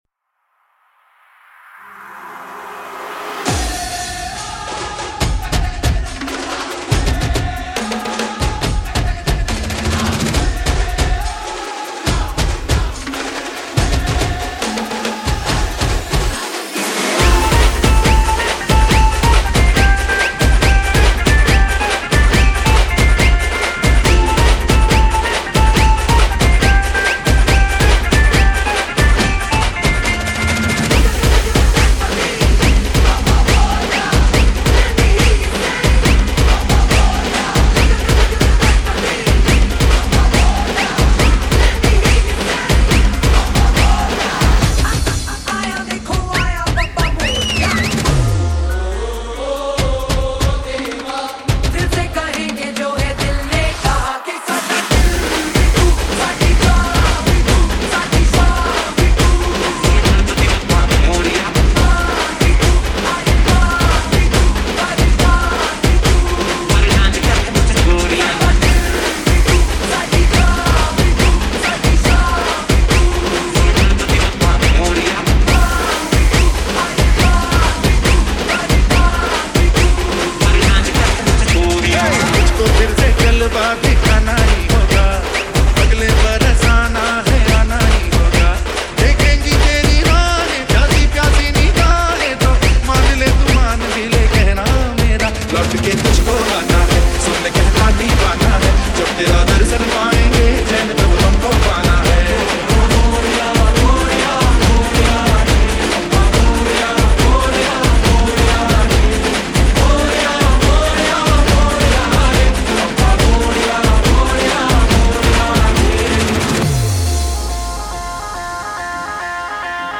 DJ Remix Mp3 Songs > Latest Single Dj Mixes